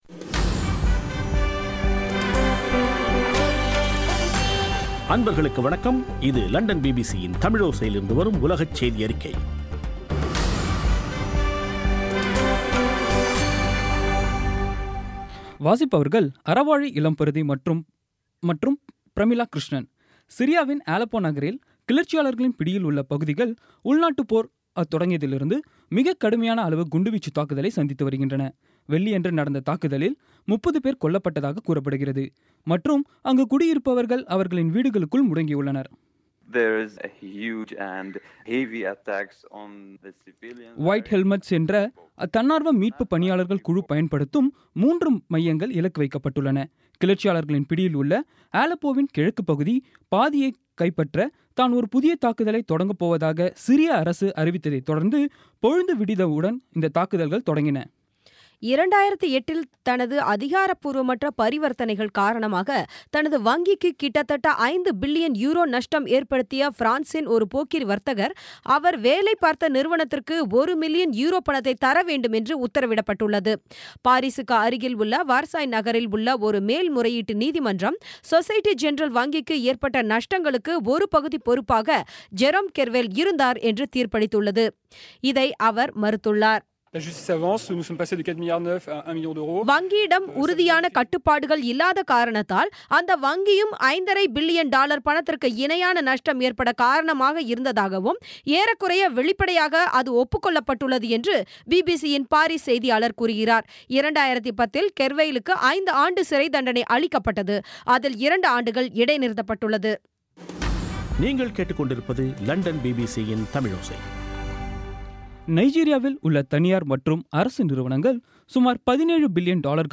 இன்றைய (செப்டம்பர் 23ம் தேதி ) பிபிசி தமிழோசை செய்தியறிக்கை